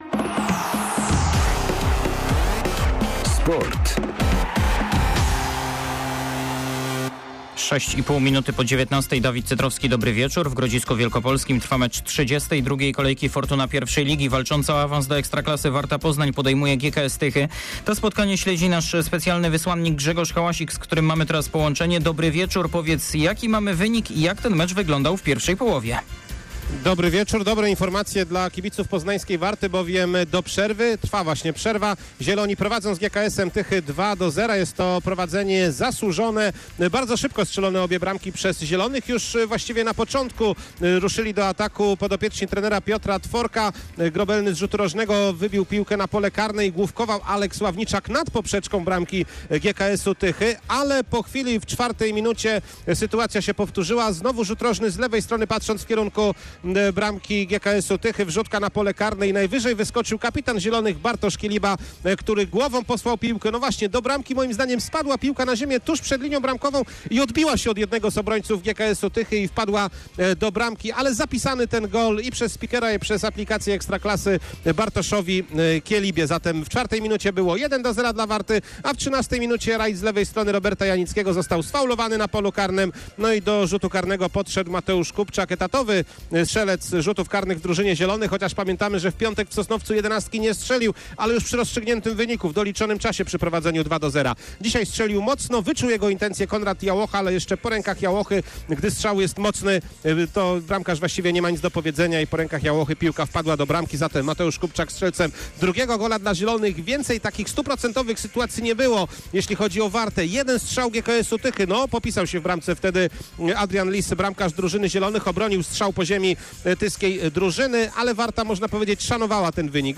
14.07. SERWIS SPORTOWY GODZ. 19:05